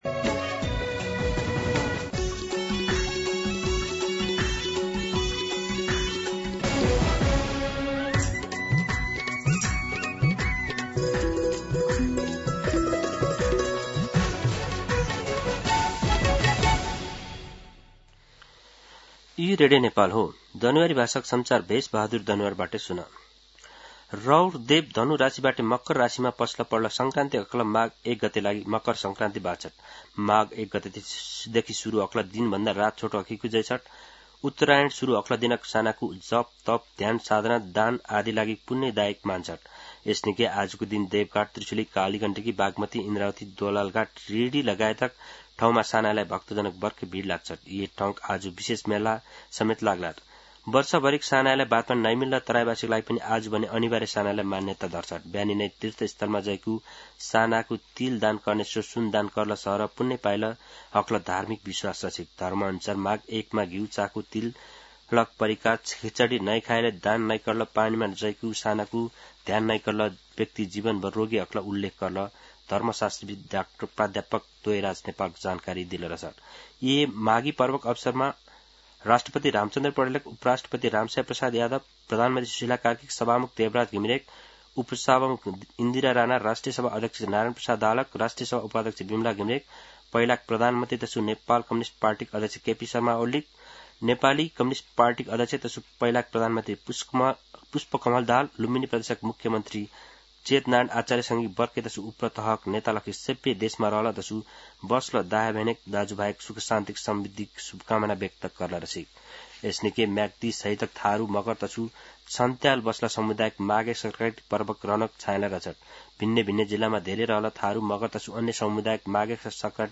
दनुवार भाषामा समाचार : १ माघ , २०८२
Danuwar-News-10-1.mp3